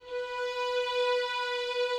strings_059.wav